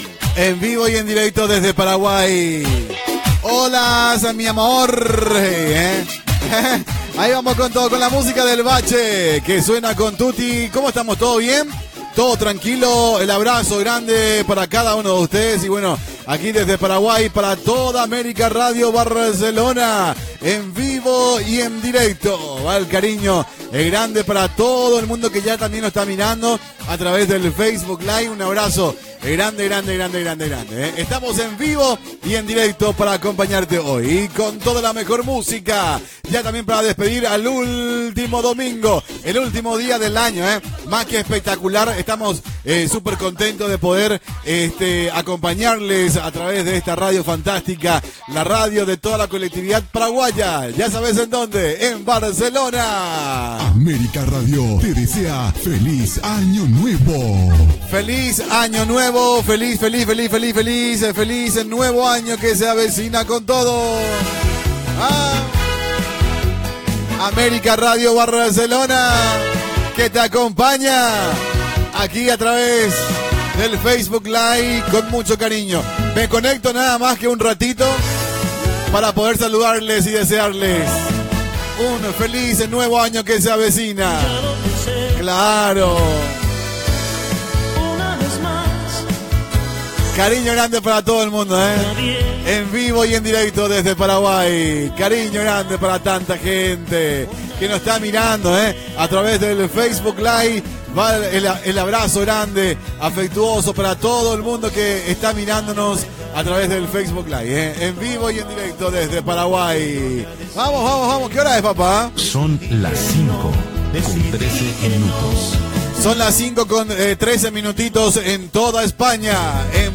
Tema musical, hora, indicatiu del programa, tema musical, salutació des de Paraguai, desig de bon any, hora, publicitat, salutacions, indicatiu
Entreteniment